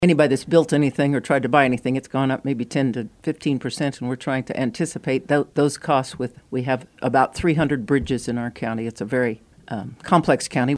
Commissioner Dee McKee explained on KMAN’s In Focus Tuesday, that it’s primarily attributed to increased personnel wages and a $1.4 million increase into the county’s Special Highway fund for future road and bridge improvements.